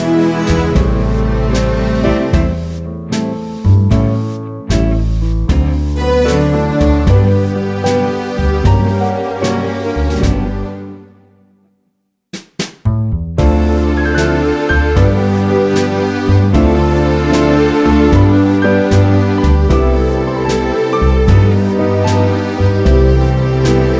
no Backing Vocals Jazz / Swing 2:55 Buy £1.50